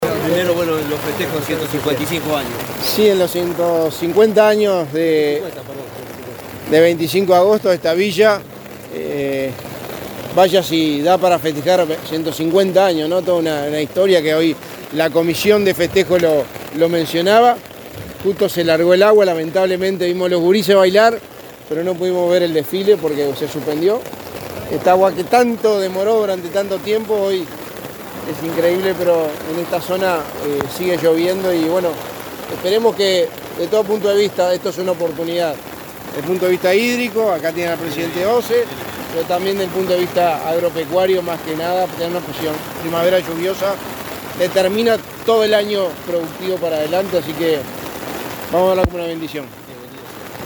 Declaraciones del secretario de Presidencia, Álvaro Delgado
Luego dialogó con la prensa.